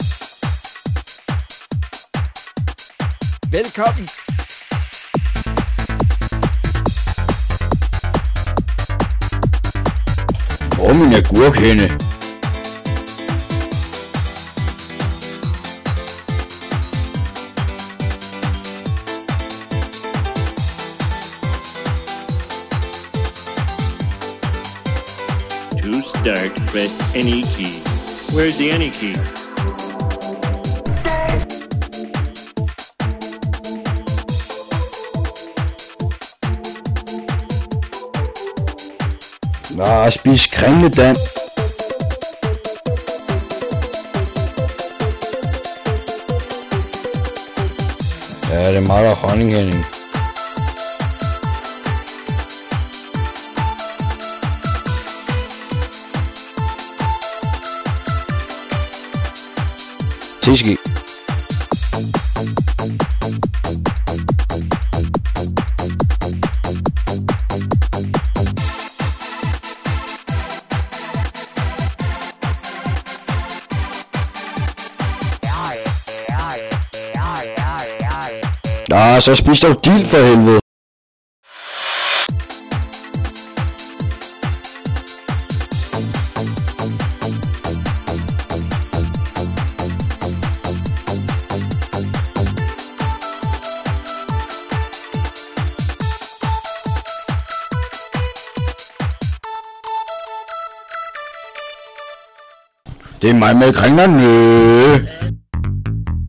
techno style
kinda nonsense rap.